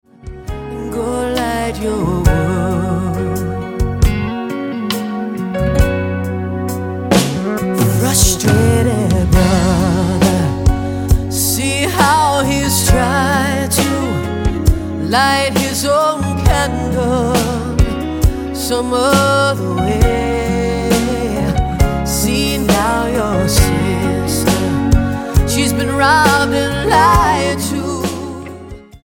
STYLE: Pop
full throated power ballad